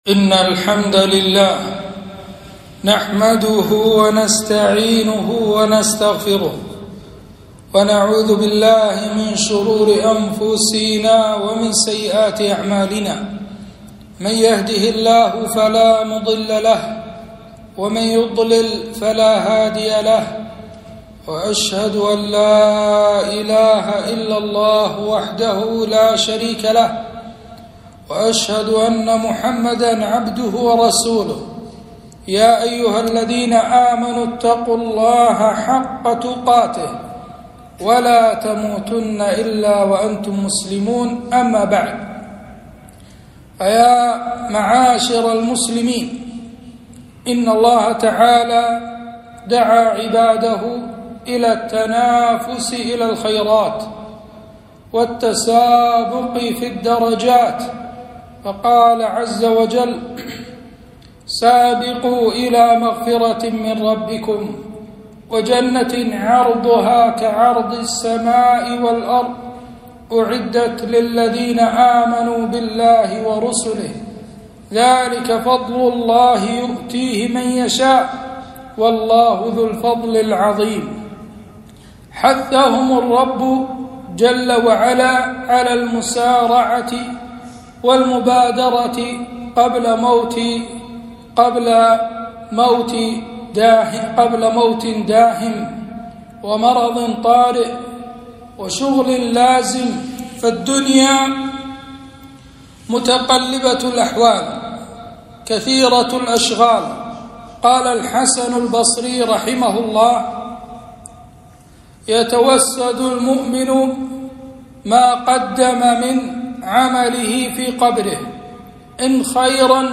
خطبة - فضل العشر من ذي الحجة